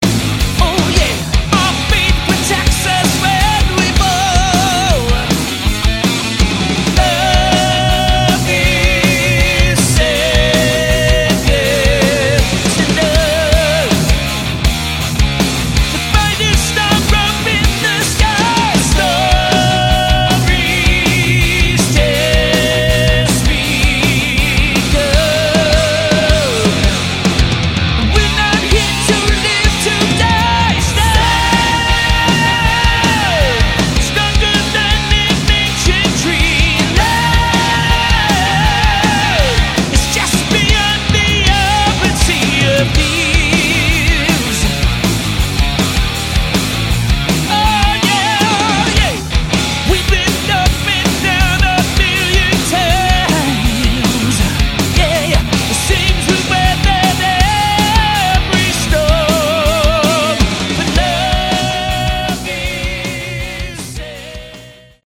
Category: Hard Rock
lead vocals, guitar
drums, background vocals